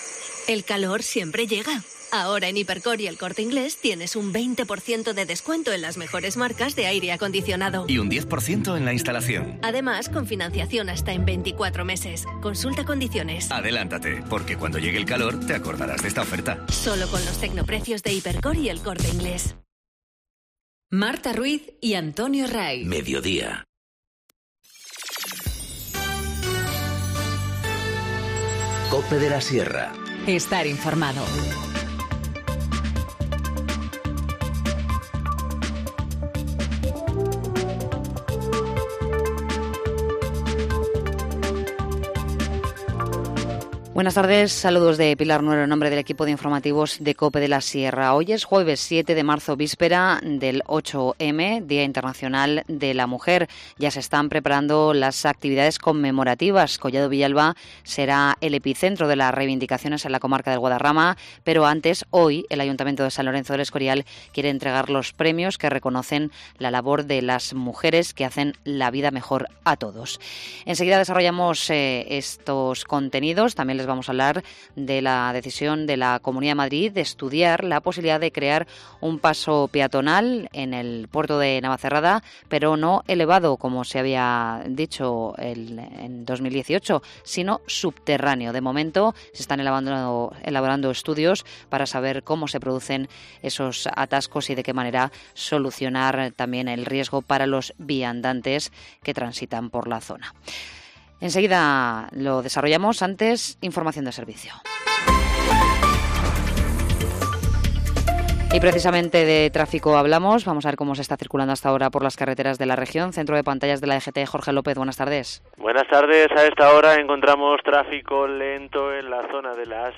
Informativo Mediodía 7 marzo 14:20h